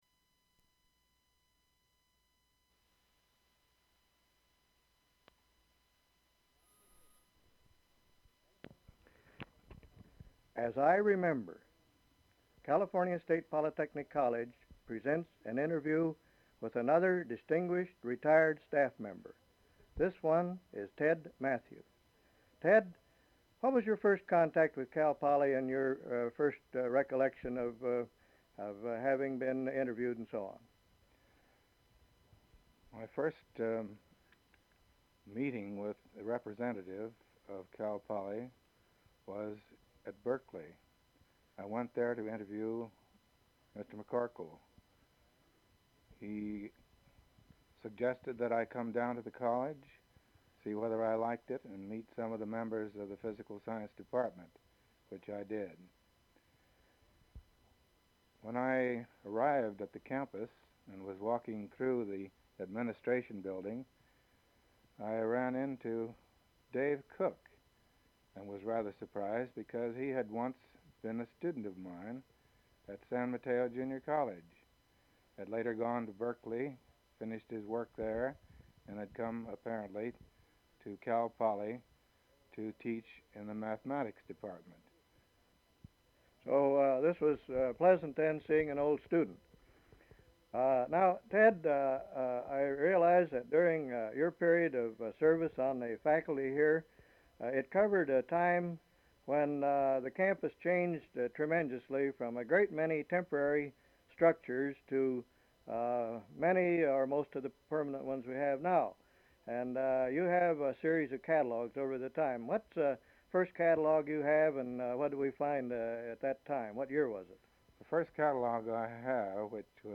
Form of original Open reel audiotape